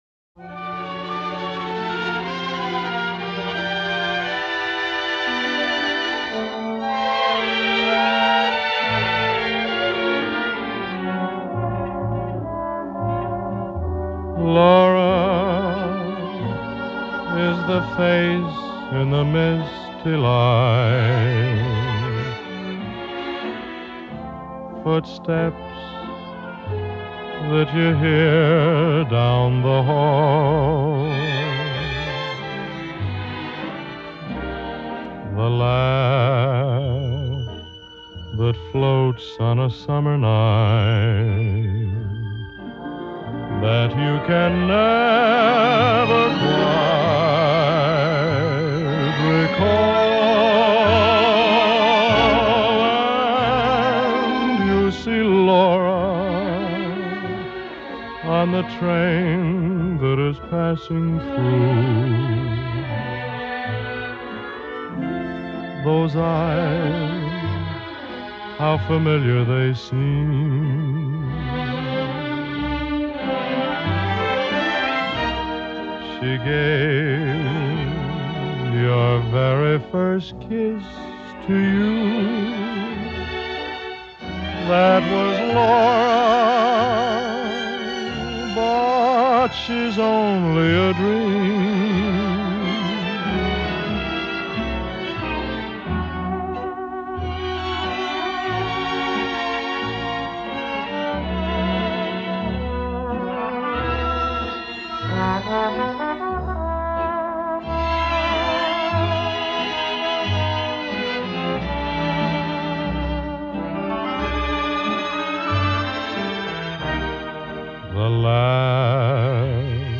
Обладатель баритона красивого глубокого тембра.